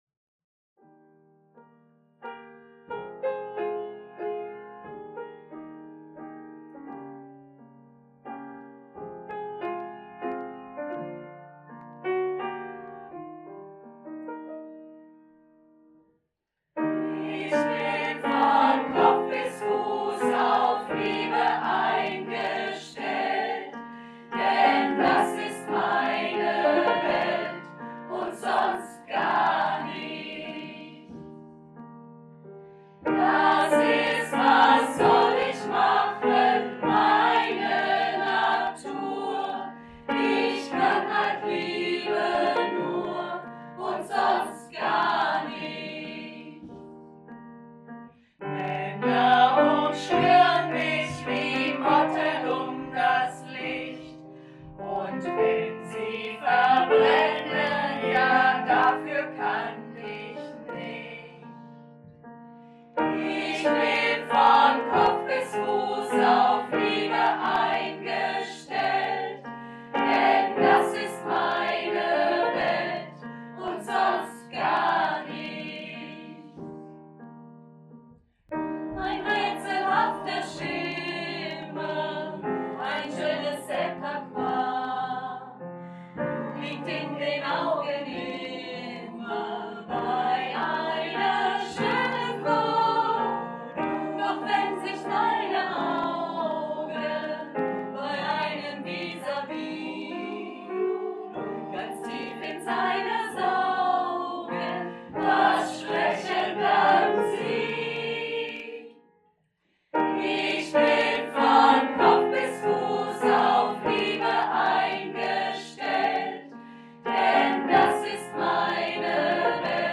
Der Frauenchor der Chrogemeinschaft besteht seit fast 50 Jahren und singt Lieder aus allen Zeiten und Genres.
Wir sind ein beständiger Chor, bestehend aus etwa 35 aktiven Sängerinnen zwischen 30 und 90 (!) Jahren, von denen einige schon seit 20, 30 und 40 Jahren zusammen singen.